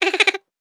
Laugh_v1_wav.wav